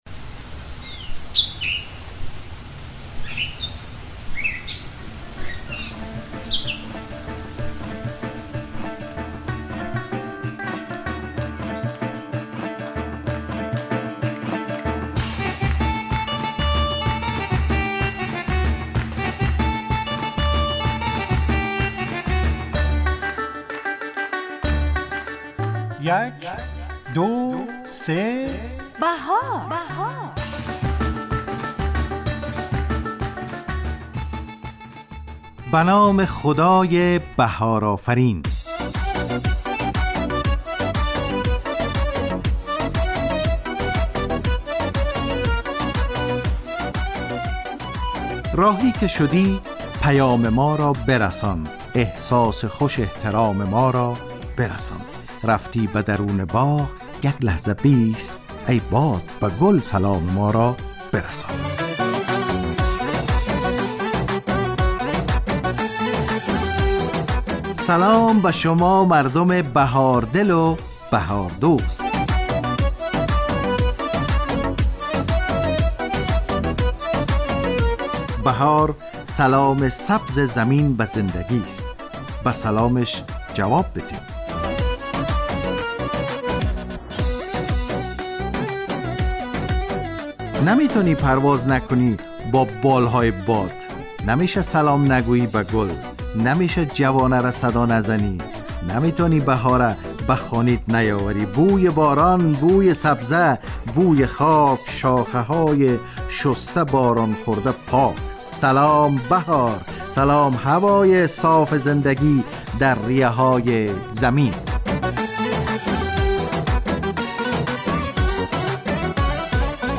یک دو سه بهار – مجله‌ی صبحگاهی رادیو دری